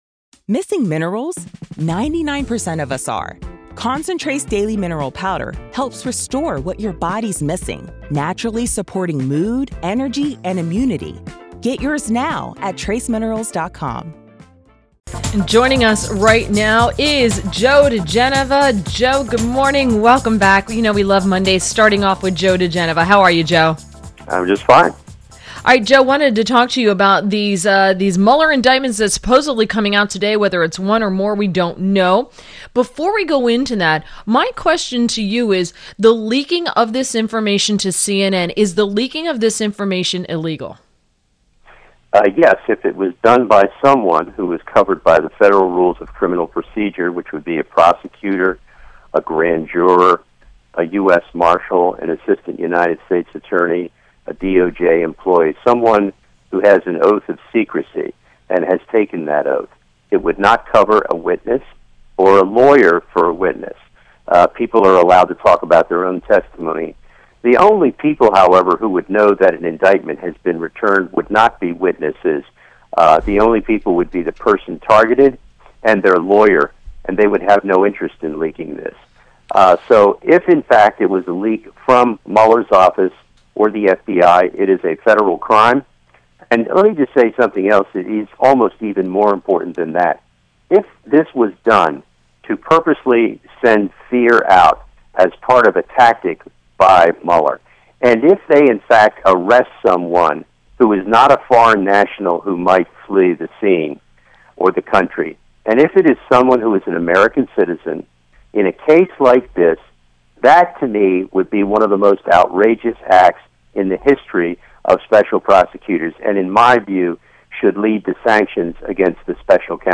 WMAL Interview - JOE DIGENOVA - 10.30.17
INTERVIEW - JOE DIGENOVA - LEGAL ANALYST AND FORMER U.S. ATTORNEY TO THE DISTRICT OF COLUMBIA – discussed the reported indictment in the Russia probe.